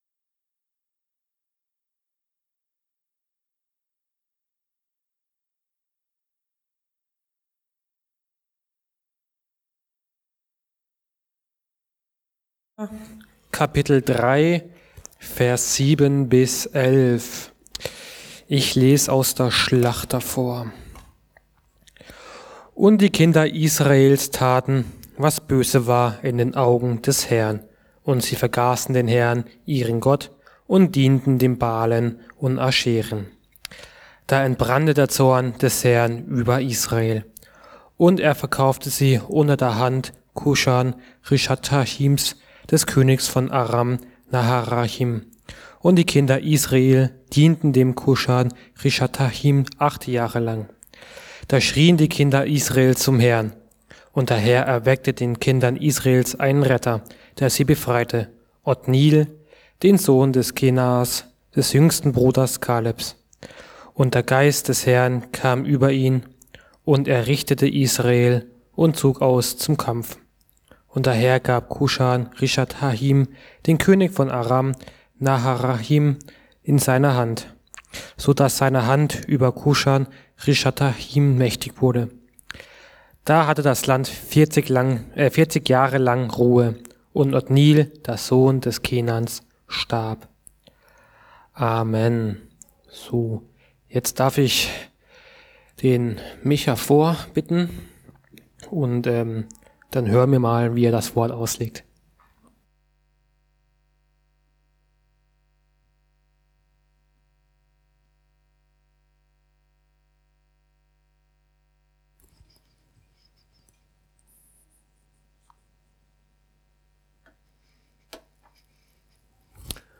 Richter -Vergessen und Erinnern ~ Mittwochsgottesdienst Podcast